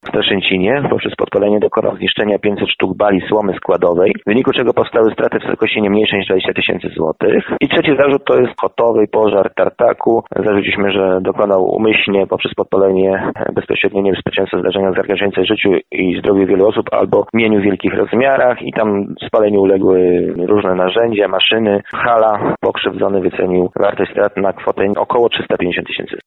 Mężczyzna jest podejrzewany o kradzież z włamaniem do domu oraz dwóch podpaleń – relacjonuje Jacek Żak prokurator rejonowy w Dębicy.